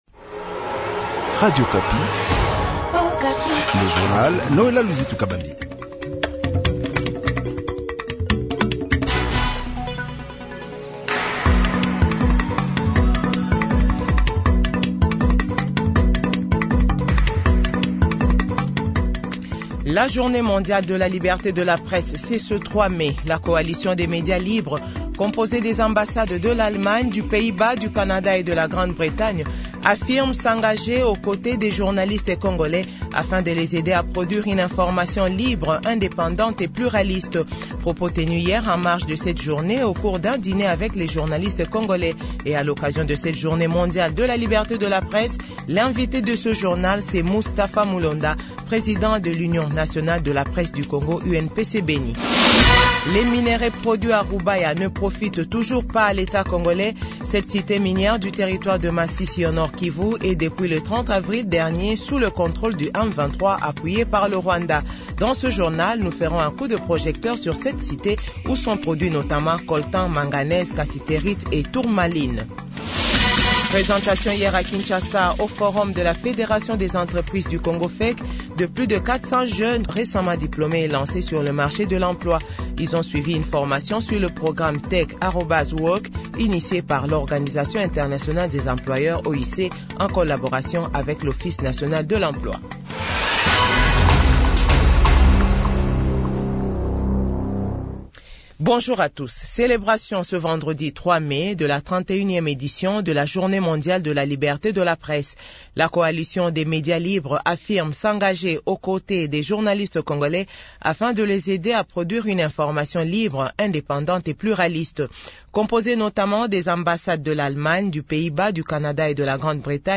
JOURNAL FRANCAIS 6H00- 7H00